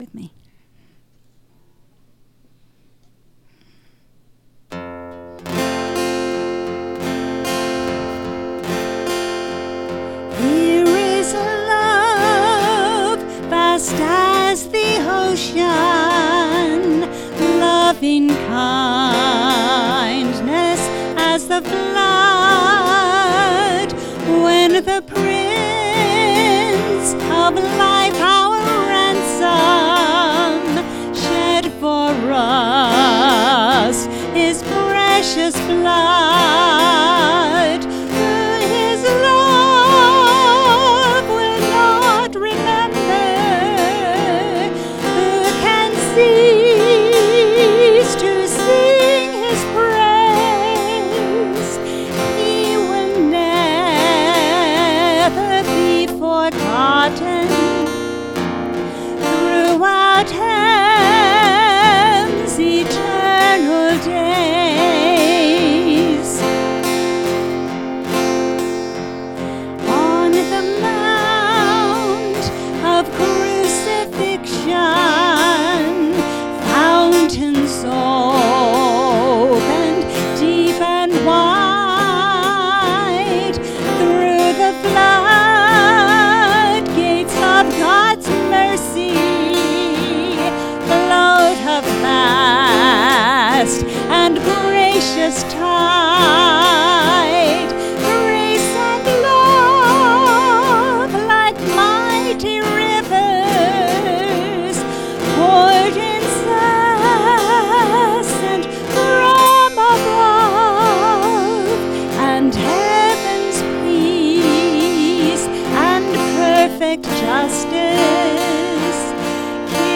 Sermon July 17